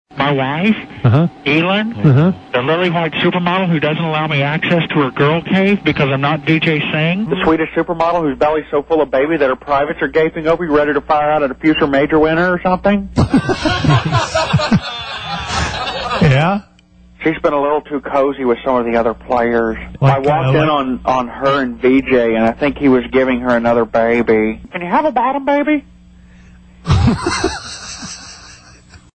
Fake Tiger explains about his wife, Elin.